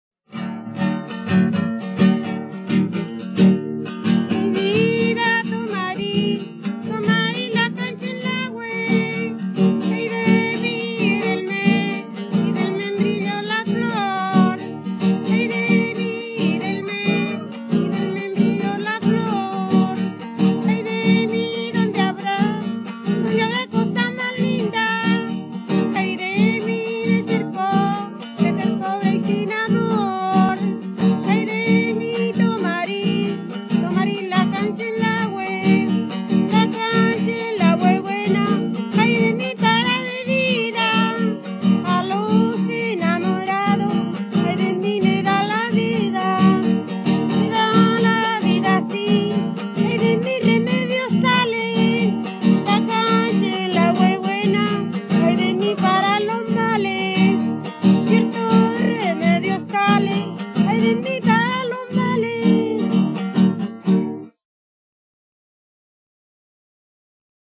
Cancionero tradicional: Tomarís la canchenlahue (cueca)
Cueca
quien se acompaña con una guitarra afinada con la tercera alta.
Música tradicional
Folklore